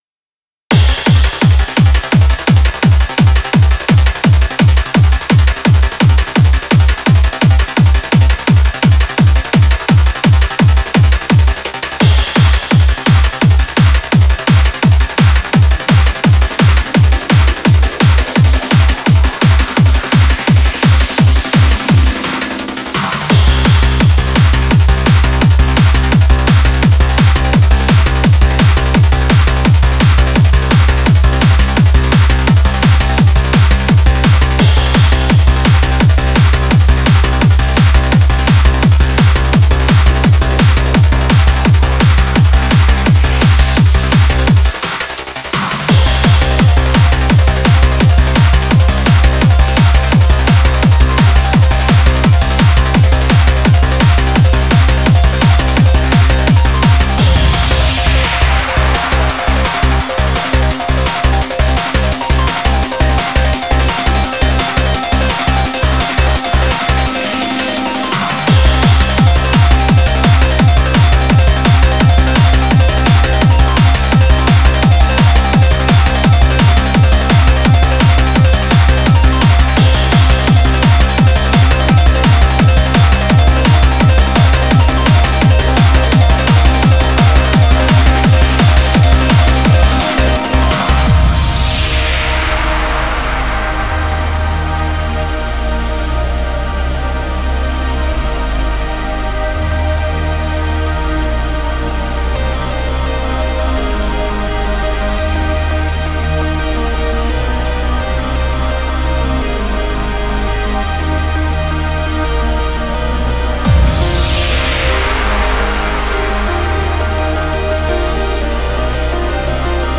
J-Core